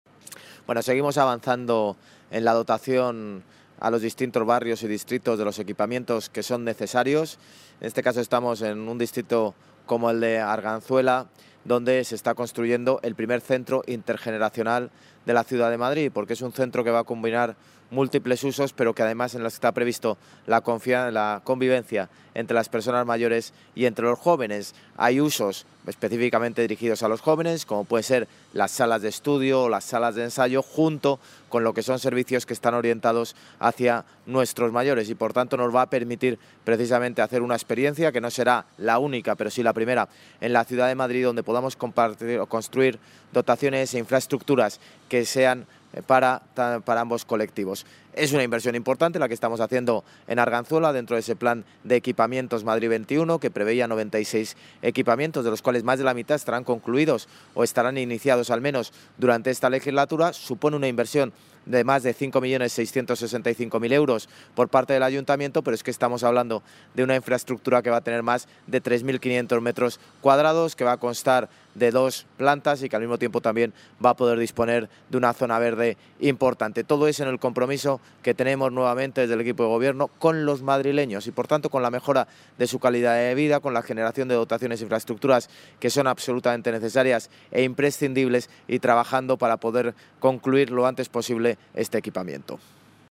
Nueva ventana:Intervención del alcalde de Madrid, José Luis Martínez-Almeida, durante la visita a las obras del Centro Intergeneracional de Arganzuela